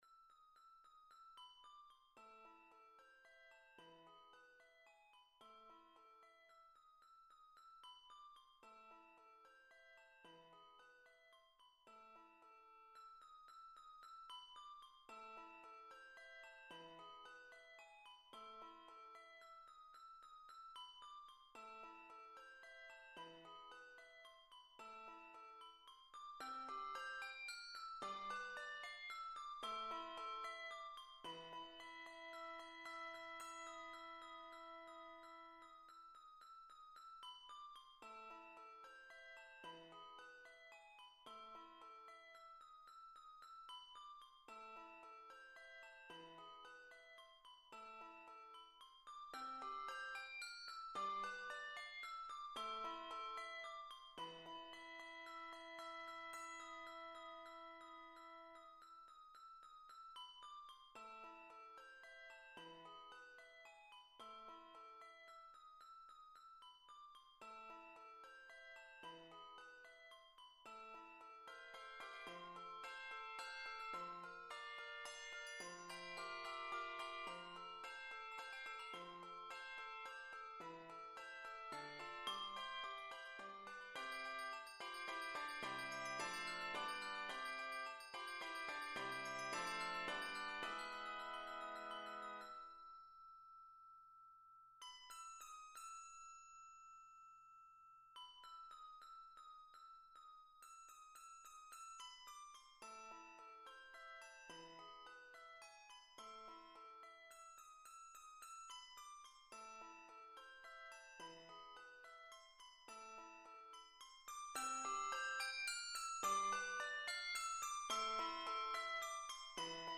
Key of a minor.